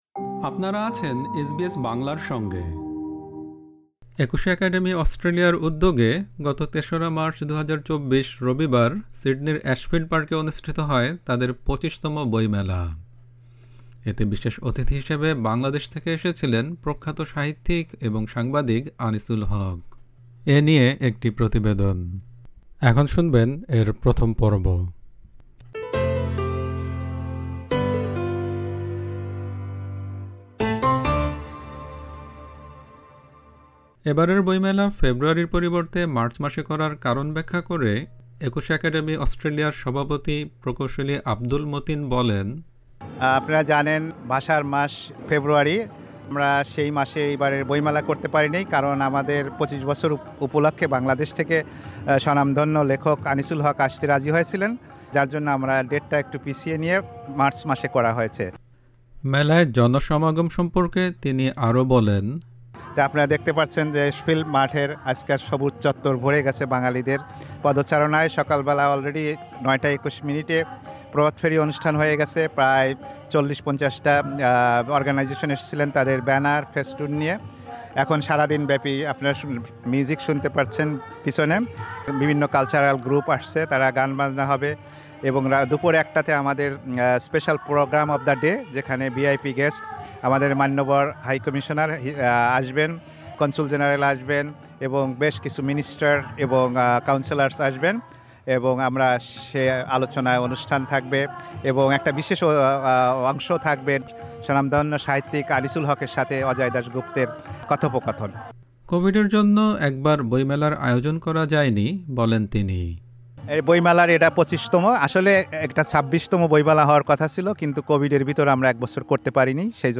একুশে একাডেমি অস্ট্রেলিয়ার উদ্যোগে গত ৩ মার্চ, ২০২৪, রবিবার সিডনির অ্যাশফিল্ড পার্কে অনুষ্ঠিত হয় তাদের ২৫তম বইমেলা। প্রতিবেদনটির প্রথম পর্বটি শুনতে উপরের অডিও-প্লেয়ারটিতে ক্লিক করুন।